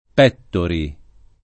[ p $ ttori ]